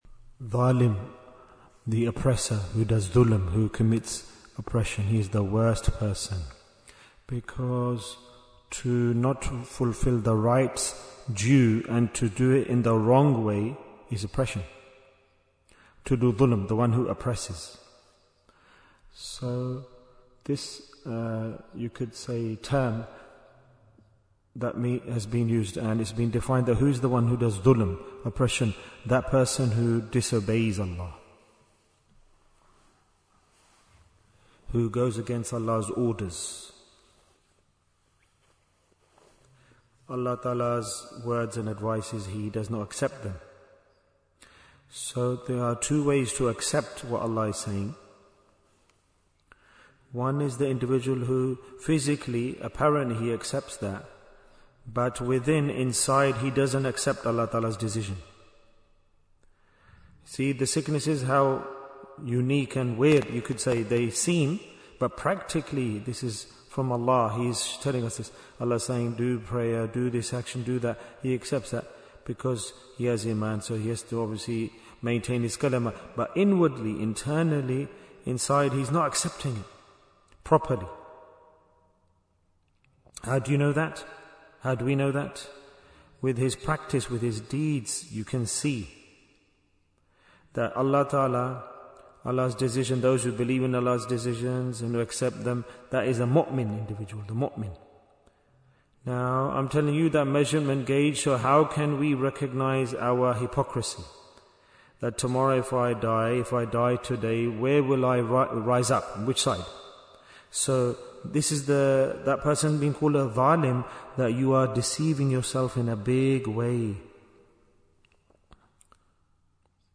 Why is Tazkiyyah Important? - Part 5 Bayan, 64 minutes17th January, 2026